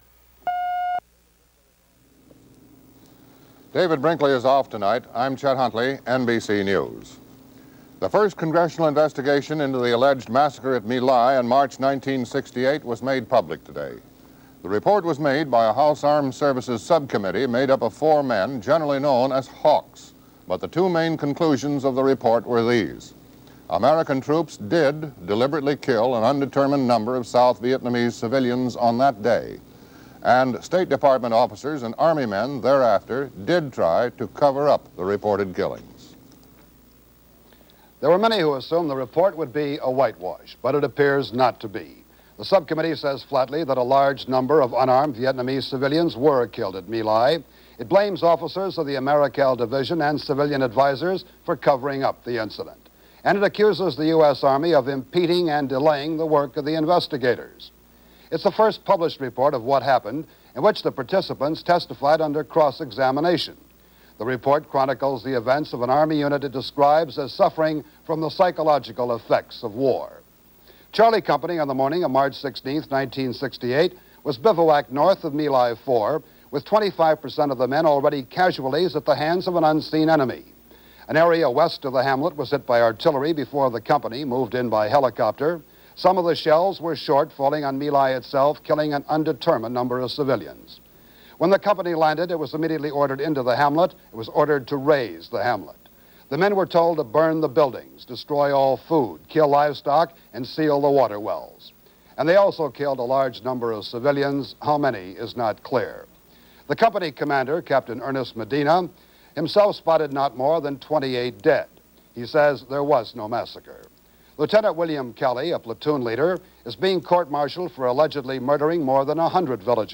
July 14, 1970 - Incident at My Lai - News of the day.